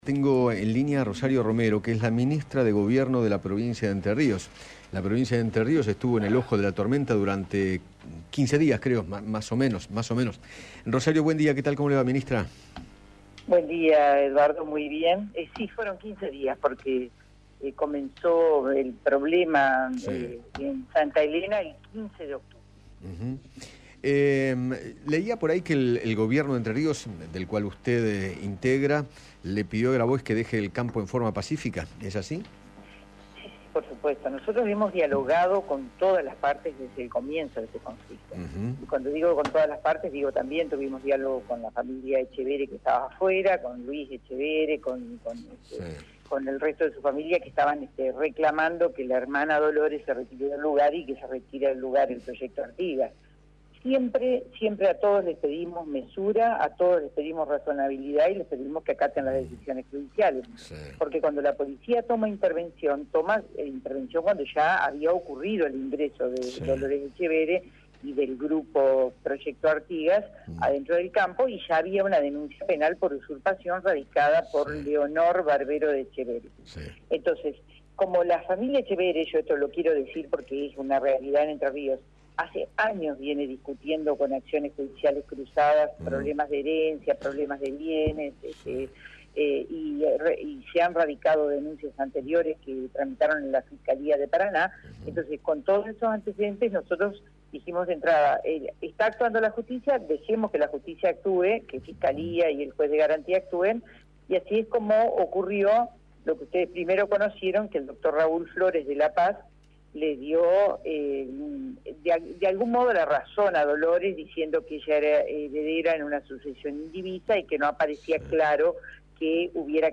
Rosario Romero, ministra de Gobierno y Justicia de Entre Ríos, habló con Eduardo Feinmann sobre el conflicto familiar de los Etchevehere por el campo “Casa Nueva” y habló del funcionamiento del sistema jurídico de aquella provincia.